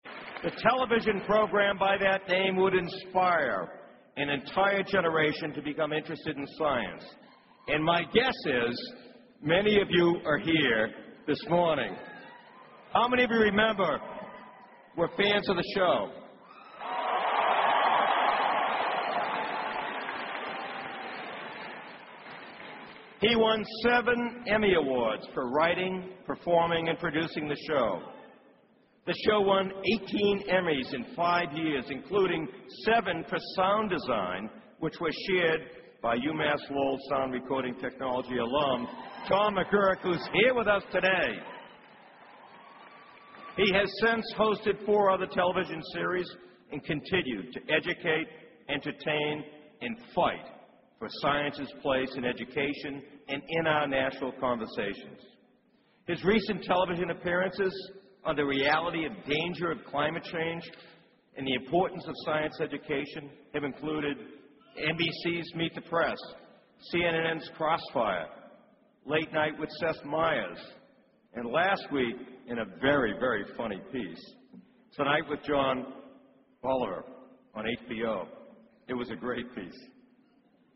公众人物毕业演讲第155期:比尔·奈马萨诸塞大学2014(2) 听力文件下载—在线英语听力室